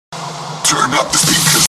Category: Sound FX   Right: Personal
Tags: Dj